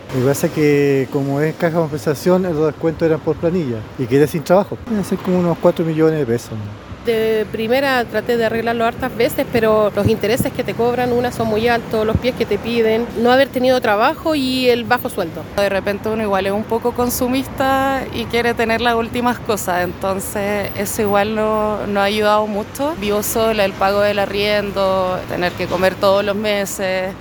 La Radio consultó a personas en situación de mora cuáles fueron los factores que las llevaron a ello, respondiendo, la mayoría, por pérdida de sus trabajos.